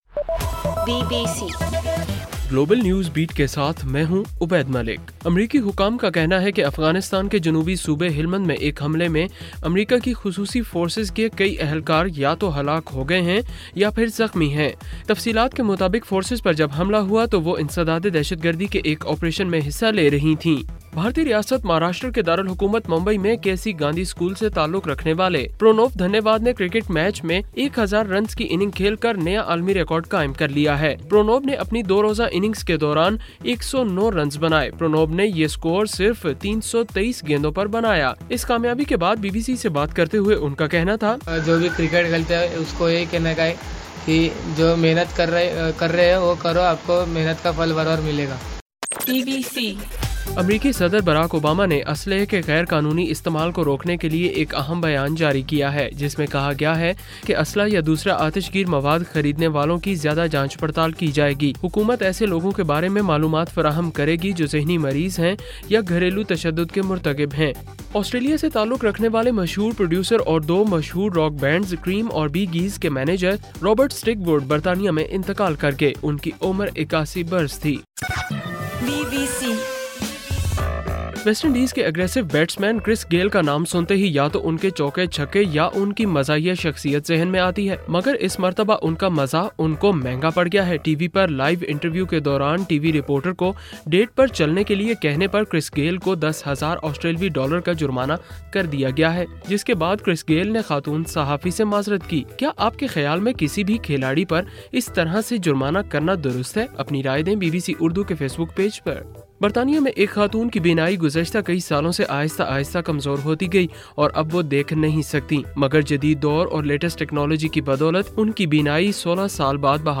جنوری 05: رات 12 بجے کا گلوبل نیوز بیٹ بُلیٹن